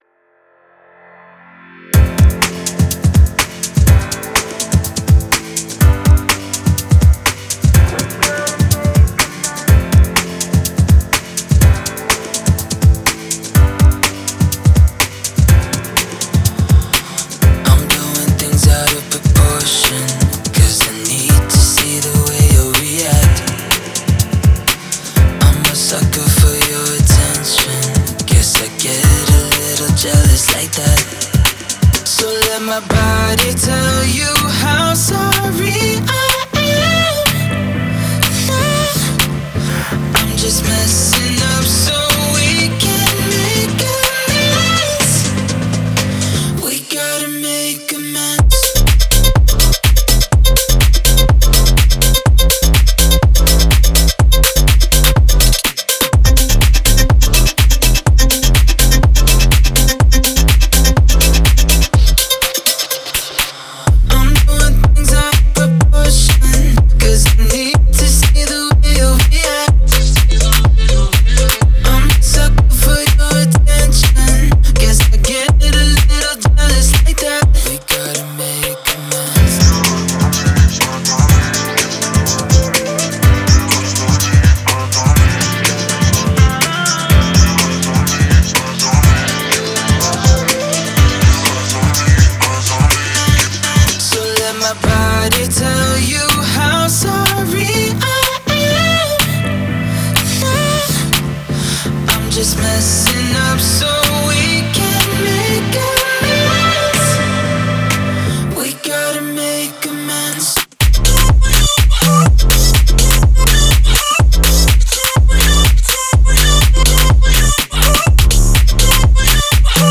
это трек в жанре электронная музыка